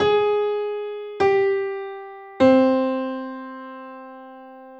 La bémol, Sol et Do forment ce premier motif.